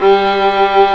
Index of /90_sSampleCDs/Roland - String Master Series/STR_Viola Solo/STR_Vla1 Bryt vb